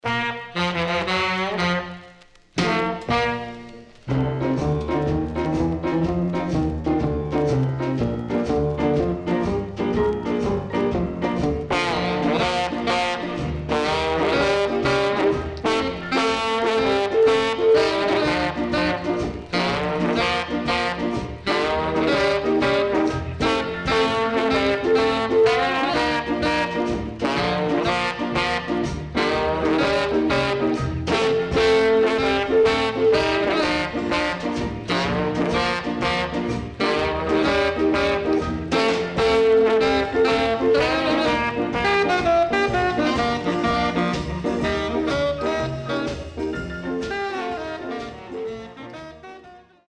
SIDE A:少しノイズ入りますが良好です。